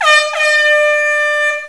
SOUND\AIRHORN.WAV